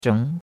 zheng2.mp3